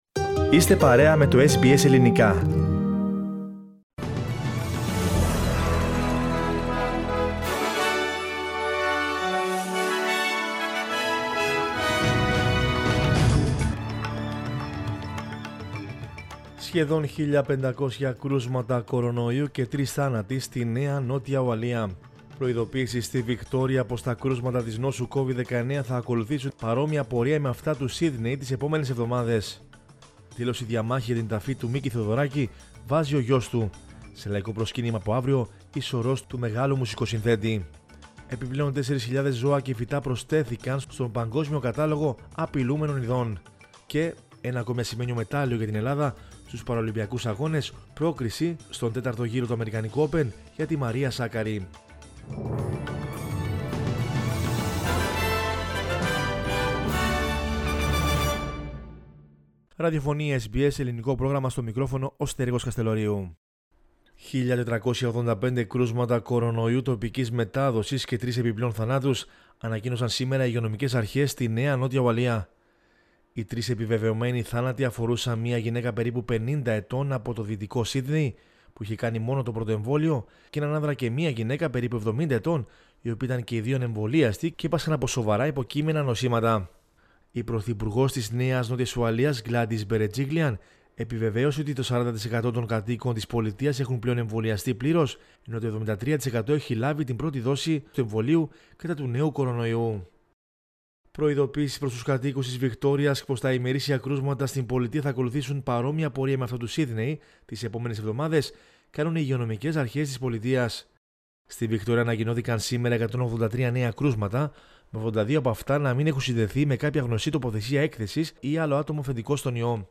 News in Greek from Australia, Greece, Cyprus and the world is the news bulletin of Sunday 5 September 2021.